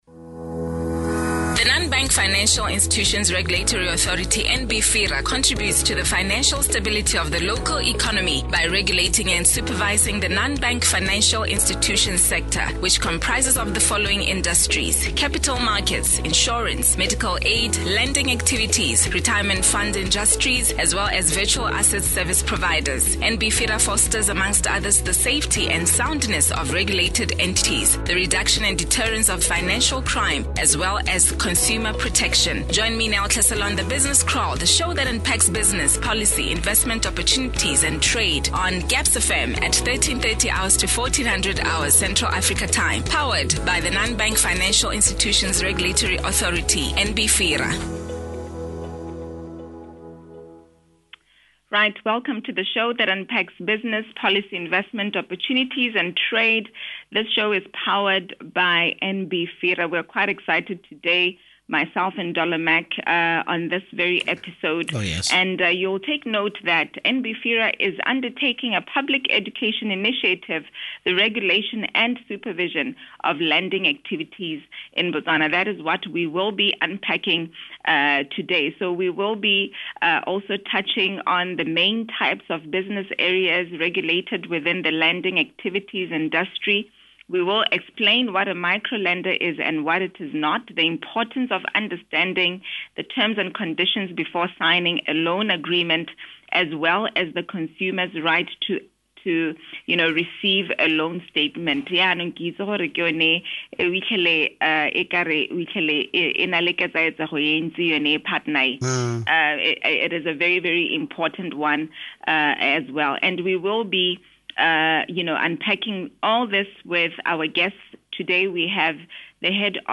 The Authority participated in an interview on GabzFM (The Business Kraal Show) to unpack the main types of business areas regulated within the lending activities industry, explain what a Micro Lender is and what it is not, the importance of understanding the Terms and conditions before signing a loan agreement as well as consumer's rights to receive a loan statement and understanding the customer. audio: NBFIRA TBK 14 JUNE 2022 .mp3